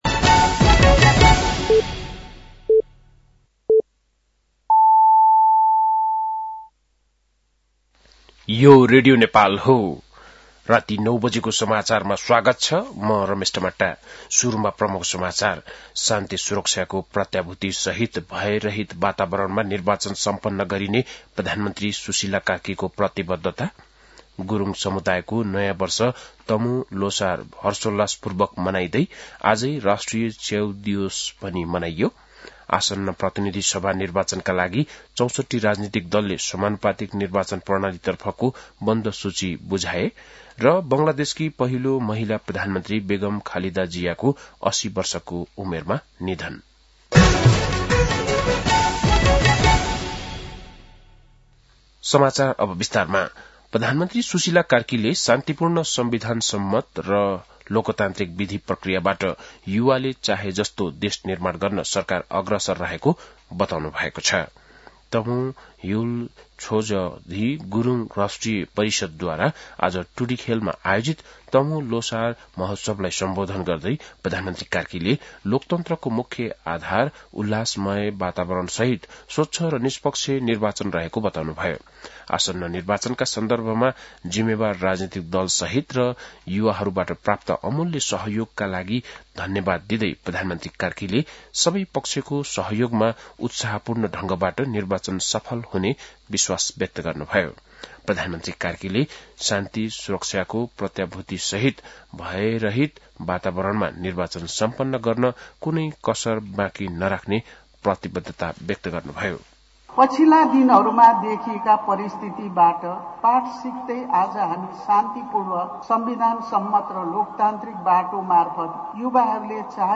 बेलुकी ९ बजेको नेपाली समाचार : १५ पुष , २०८२